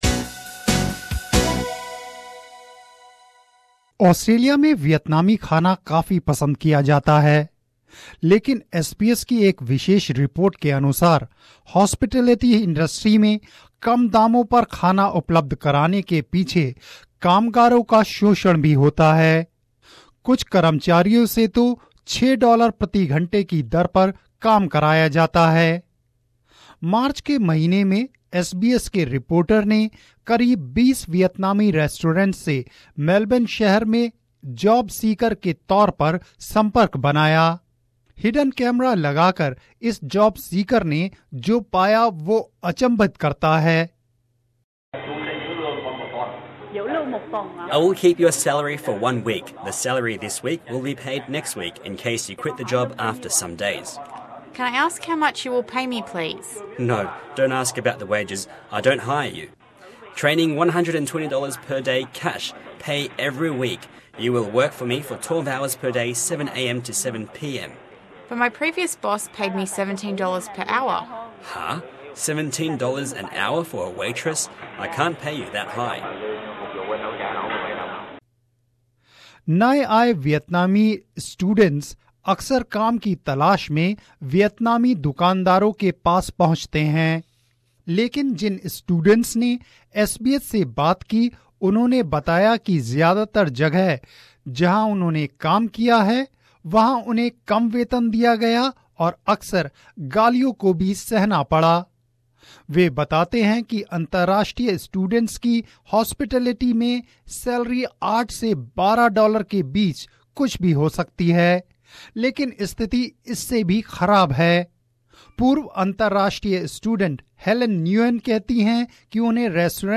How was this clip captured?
Armed with a hidden camera, this is what the "job seeker" encountered.